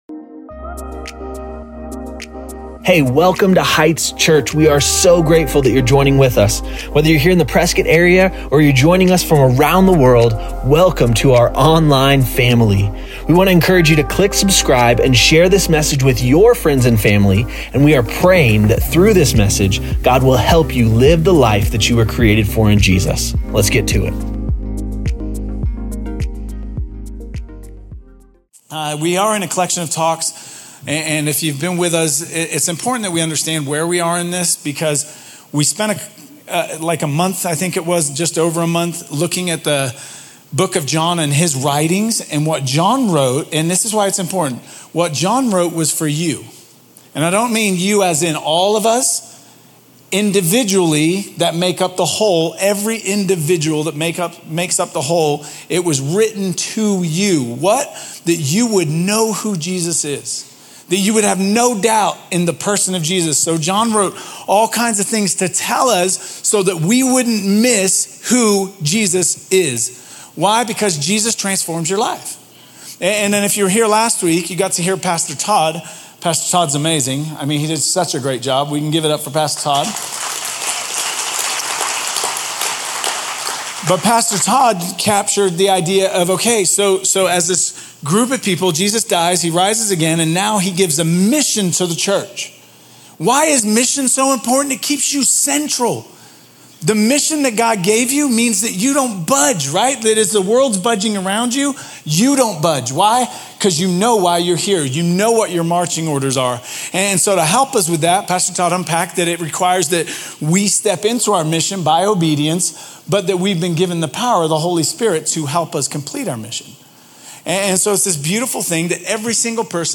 Heights Church podcast